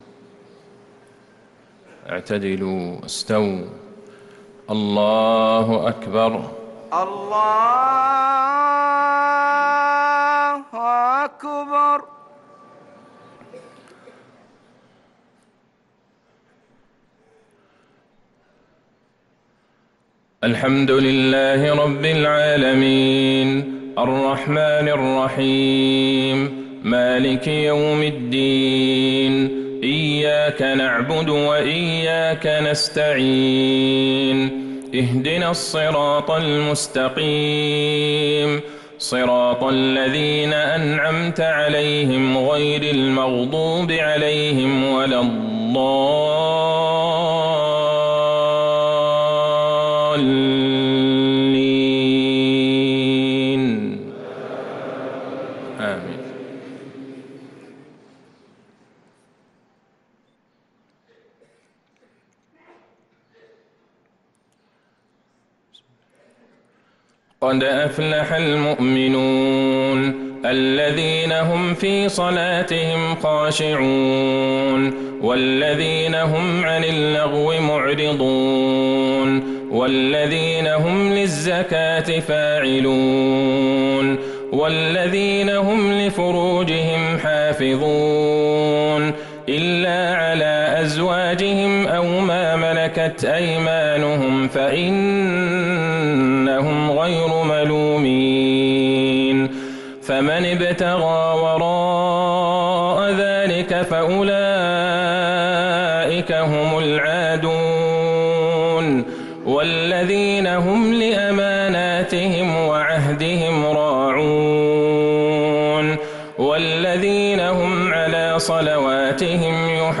صلاة المغرب للقارئ عبدالله البعيجان 18 جمادي الآخر 1445 هـ
تِلَاوَات الْحَرَمَيْن .